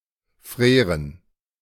Freren (German pronunciation: [ˈfʁeːʁən]